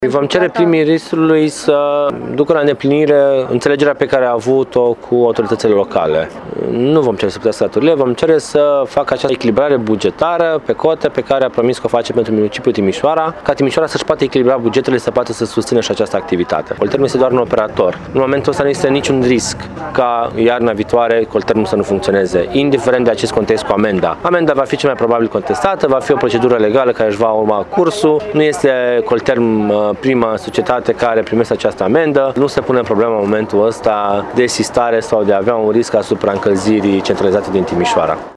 Indiferent însă de decizia premierului, cetățenii vor primi și în iarna viitoare apă caldă și căldură de la Colterm, dă asigurări viceprimarul Ruben Lațcău.